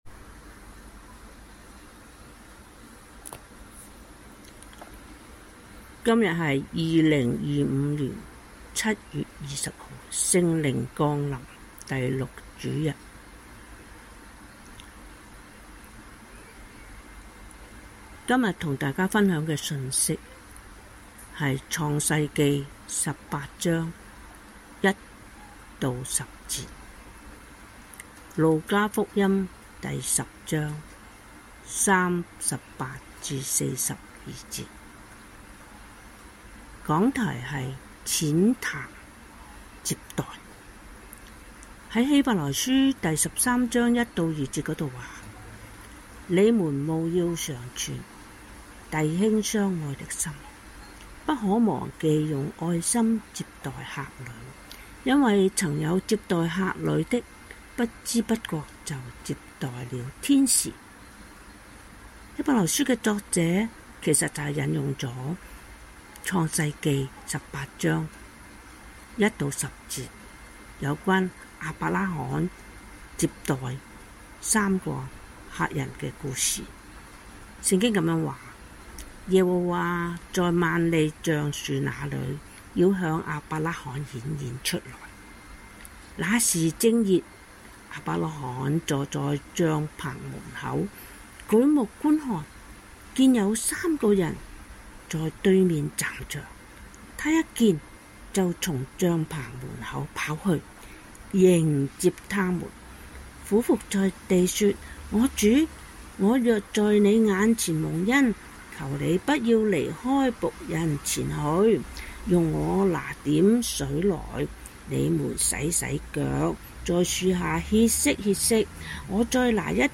基督教香港信義會灣仔堂 - 講道重溫